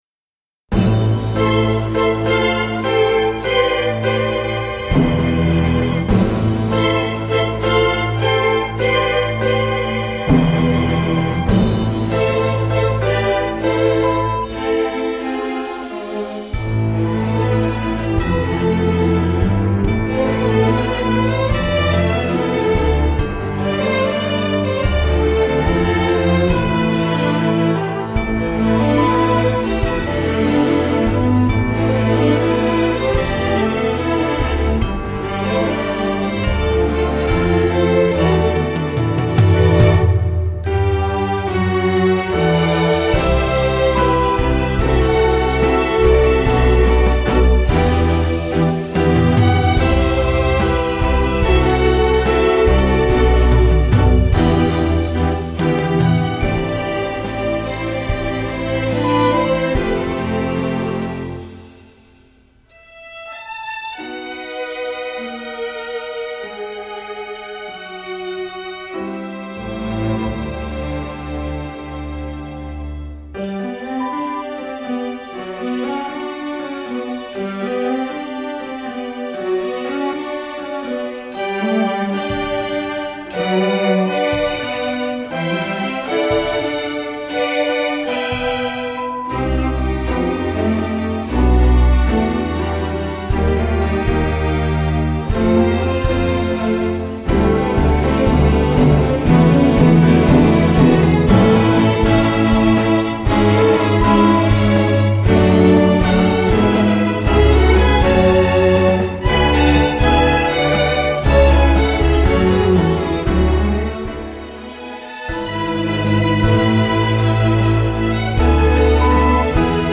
Category: String Orchestra
Instrumentation: str=8.8(4).4.4.4,pf,perc